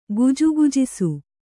♪ gujugujisu